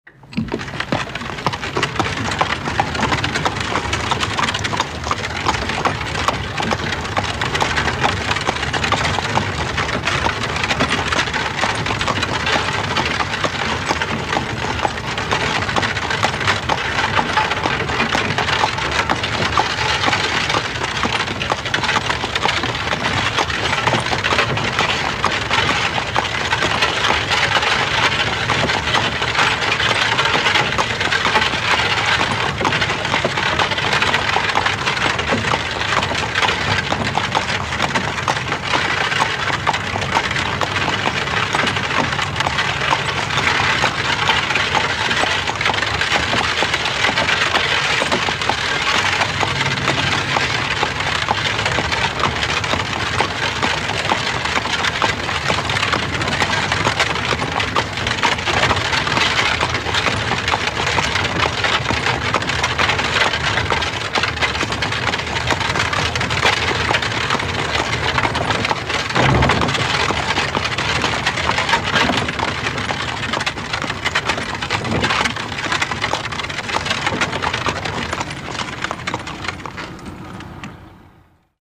Погрузитесь в атмосферу прошлого с натуральными звуками повозки: скрип деревянных колес, цокот копыт лошадей, стук по неровной дороге.
Звук лошади с телегой удаляется